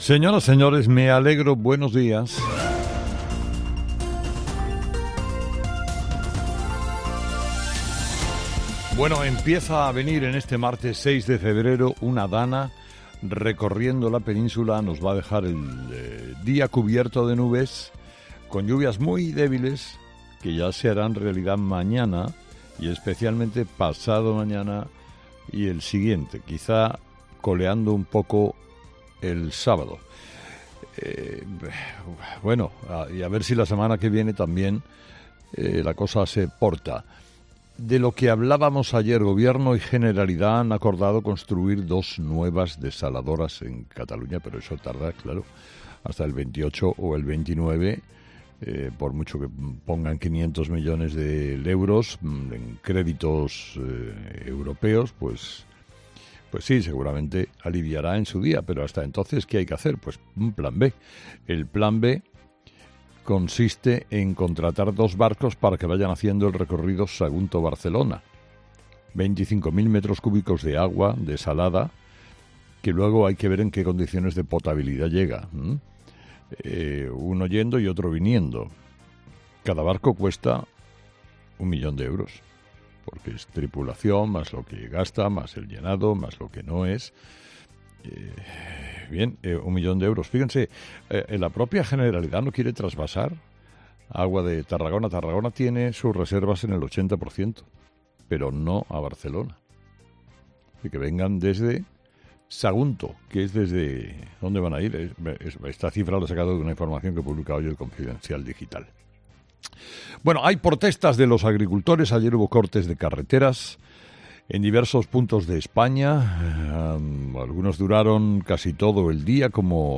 Escucha el análisis de Carlos Herrera a las 06:00 en Herrera en COPE del martes 6 de febrero